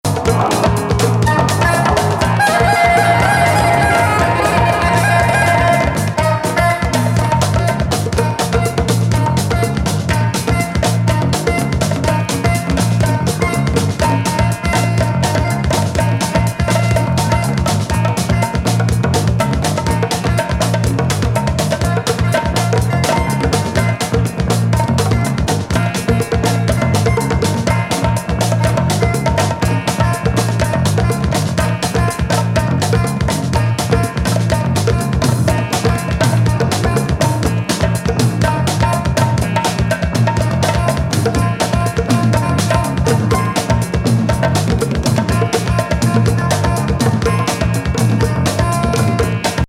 奇トライバル・ビート!インダストリアル,ノイズ・コラージュも有りの怪作!